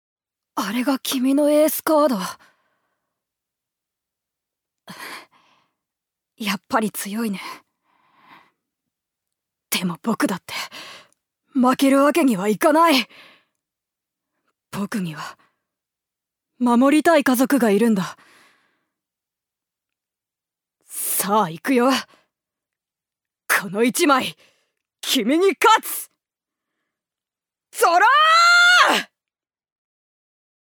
ジュニア：女性
セリフ３